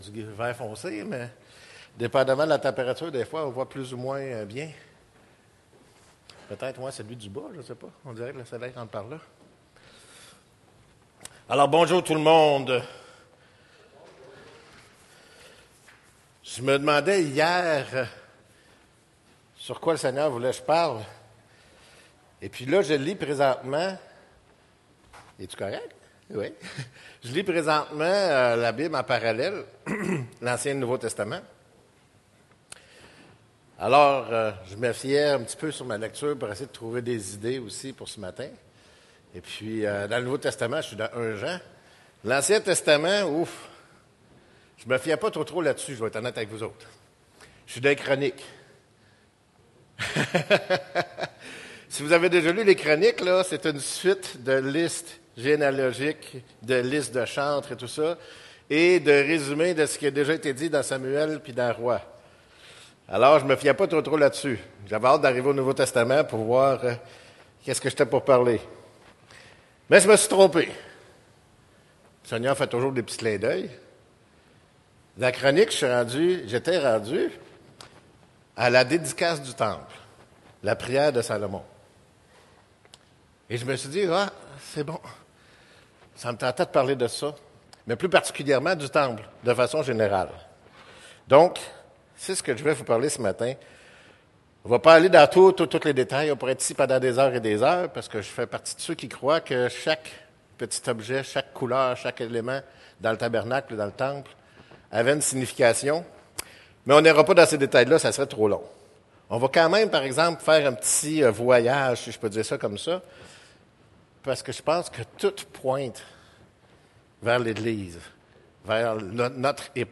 7 décembre 2025 ← Prédication précédent Prédication suivant →